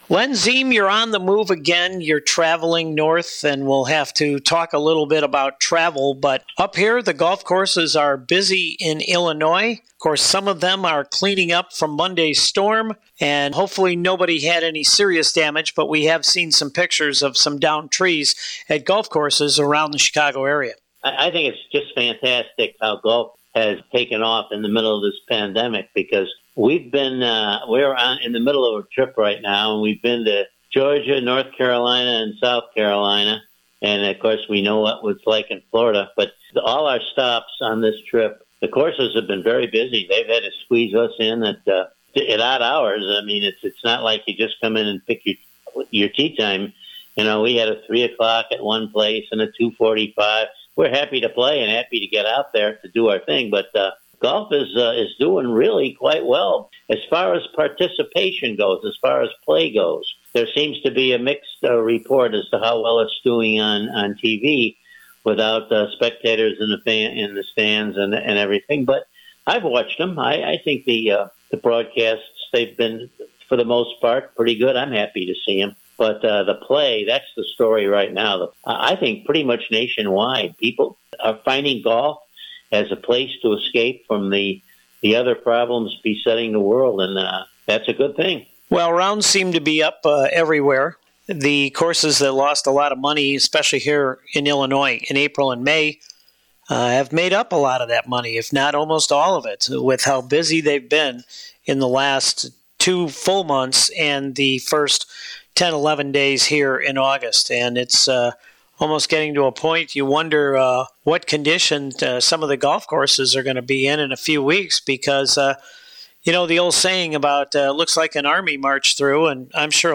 recording from the Golfers on Golf Studio’s in North West Suburban Arlington Heights